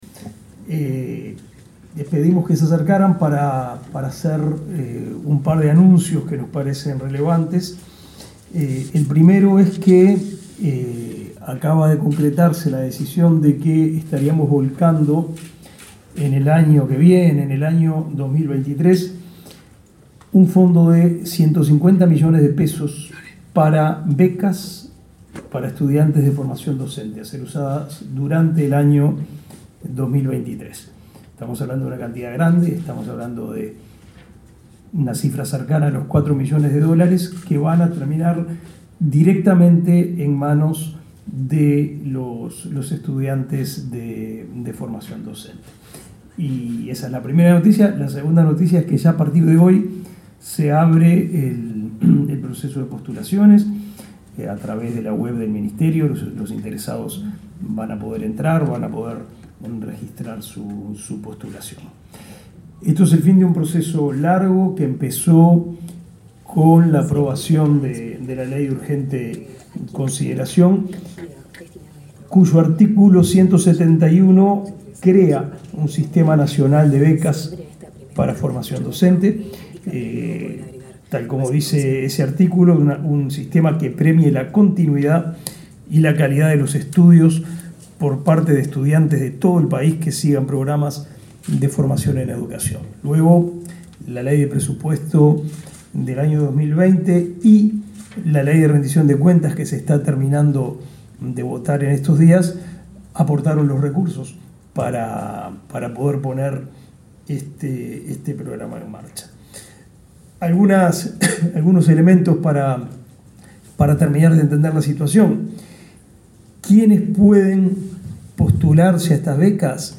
Palabras del ministro de Educación y Cultura